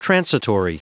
Prononciation du mot transitory en anglais (fichier audio)